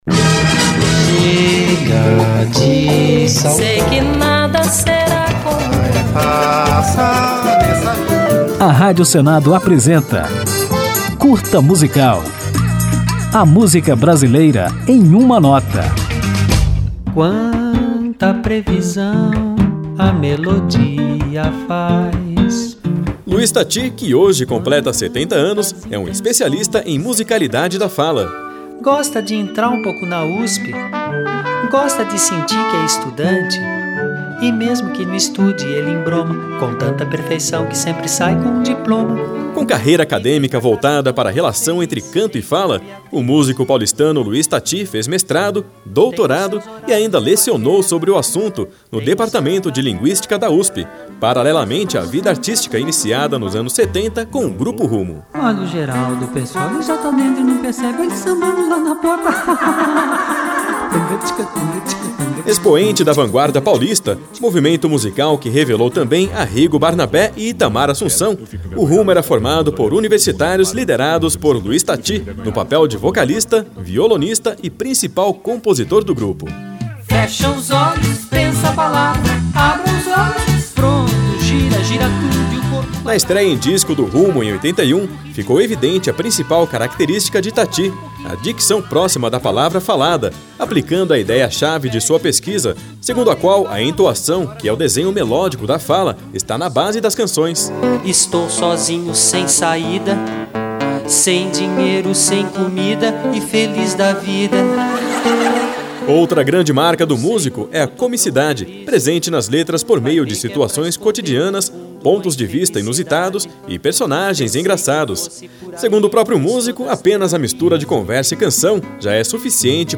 Conjunto de pílulas radiofônicas sobre a MPB, nas quais o ouvinte pode conferir fatos, curiosidades, informações históricas e ainda ouvir uma música ao final de cada edição.